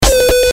Sound Buttons: Sound Buttons View : MC Ride Noise
mc-ride-noise.mp3